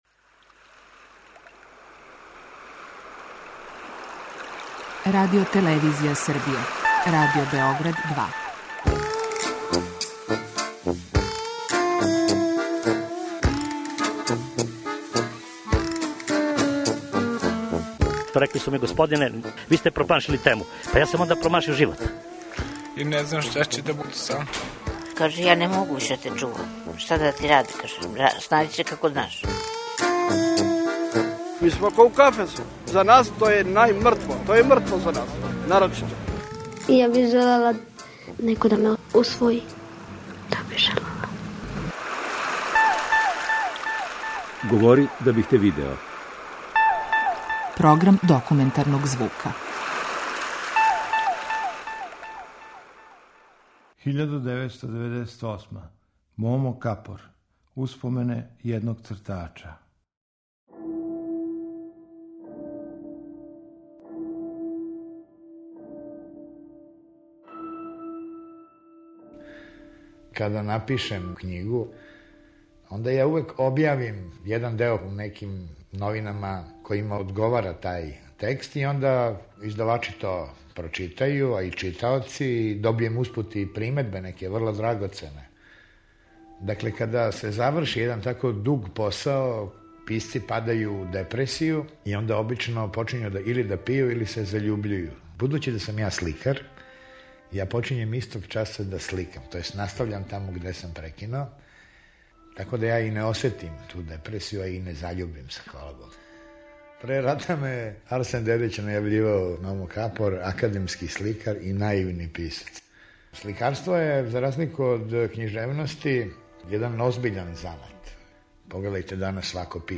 Документарни програм
Успомене једног цртача. Приповеда Момо Капор.